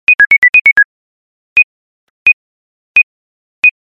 • Качество: 256, Stereo
спокойные
без слов
инструментальные
короткие
электронные